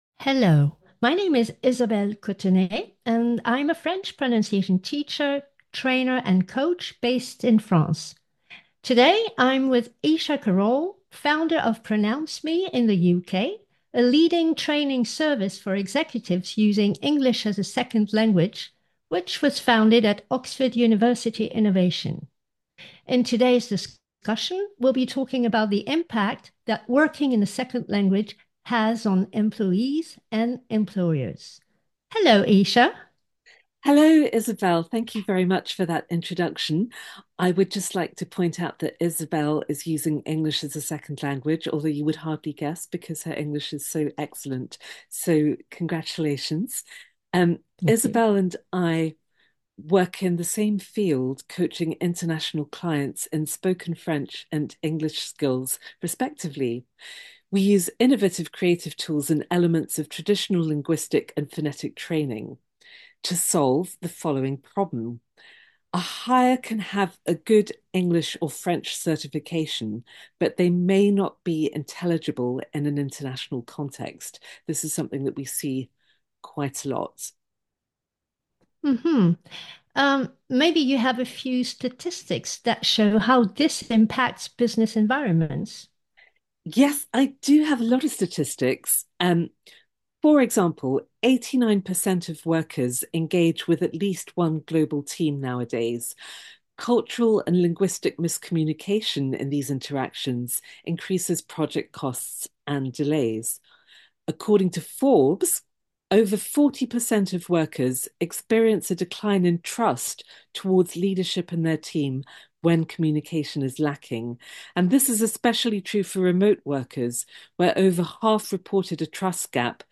In this podcast discussion, you will find the critical role of French pronunciation for professionals working in a second language.